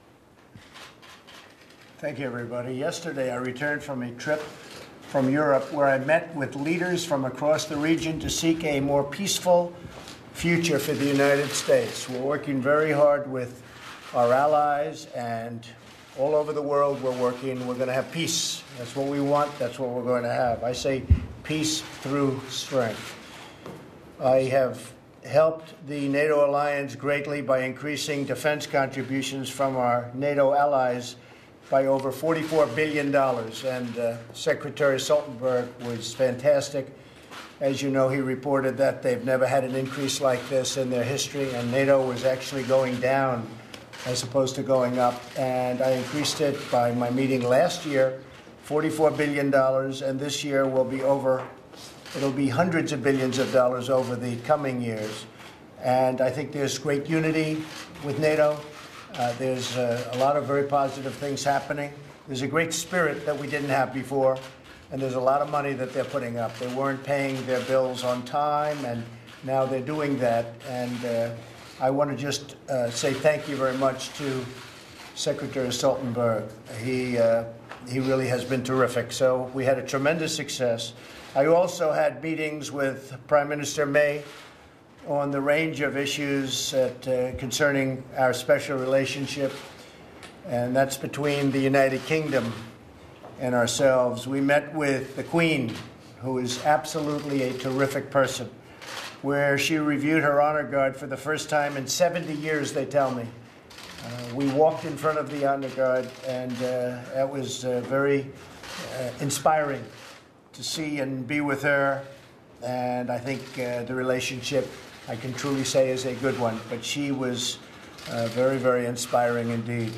Prior to a meeting with Congressional Republicans, U.S. President Donald Trump clarifies statements he made the previous day at a news conference with Russian President Vladimir Putin about Russian interference in the 2016 presidential election. Trump says that he accepts the findings of U.S. intelligence agencies and that he meant to say that he could see why Russia was involved. He also recounts some of his recent foreign policy accomplishments.